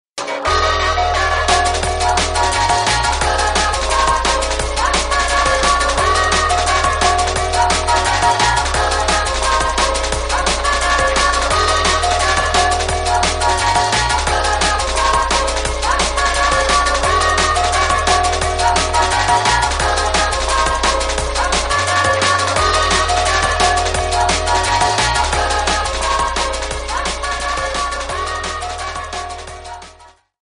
TOP > Deep / Liquid